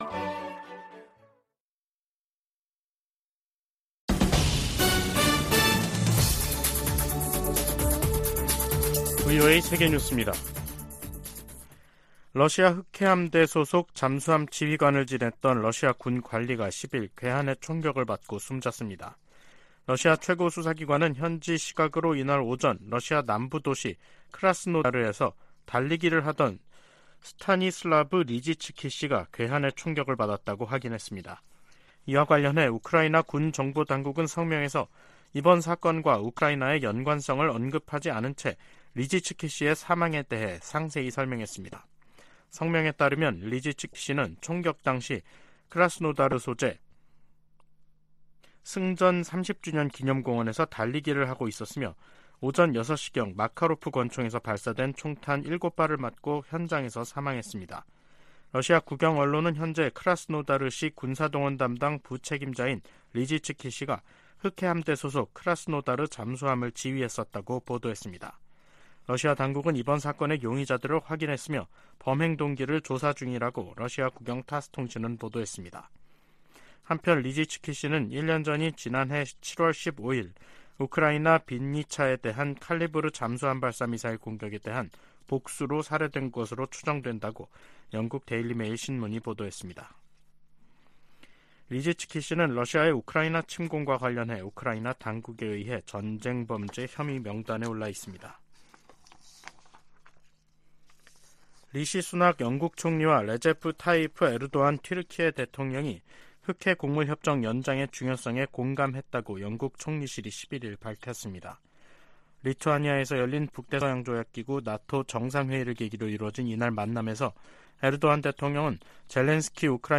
VOA 한국어 간판 뉴스 프로그램 '뉴스 투데이', 2023년 7월 11일 3부 방송입니다. 북한 김여정 노동당 부부장이 이틀 연속 미군 정찰기의 자국 상공 침범을 주장하며 군사적 대응을 시사하는 담화를 발표했습니다. 이와 관련해 미 국무부는 북한에 긴장 고조 행동 자제를 촉구했습니다. 미한 핵 협의그룹 NCG 첫 회의가 다음 주 서울에서 열립니다.